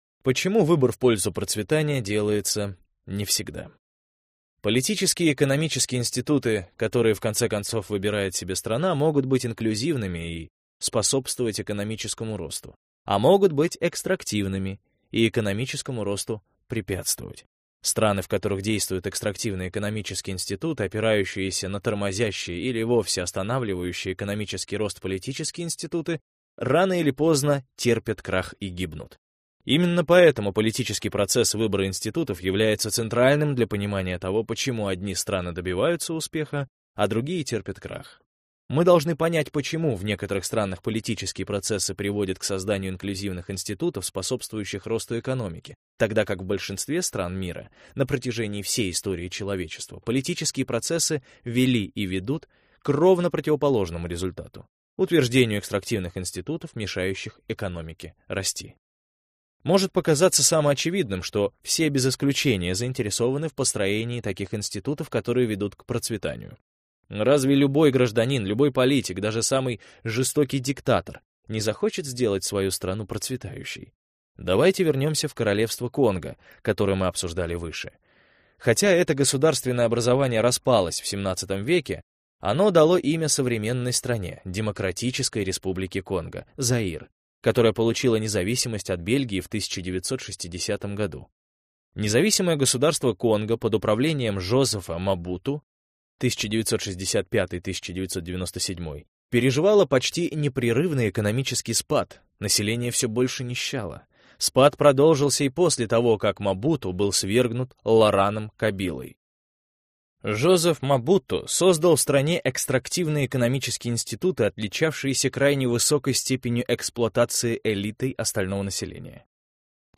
Аудиокнига Почему одни страны богатые, а другие бедные. Происхождение власти, процветания и нищеты - купить, скачать и слушать онлайн | КнигоПоиск